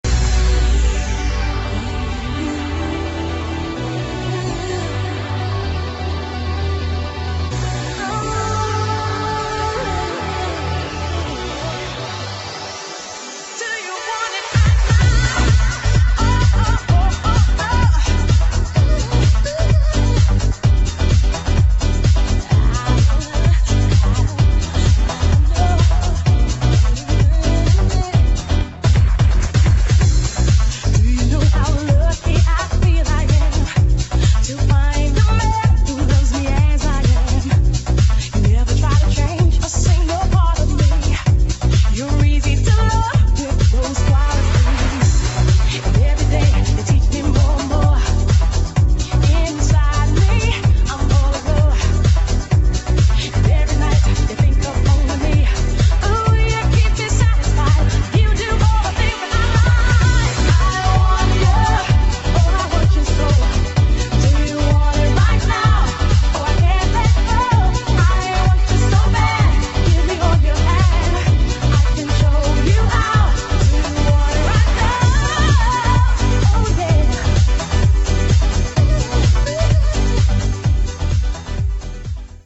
[ FUNKY HOUSE ]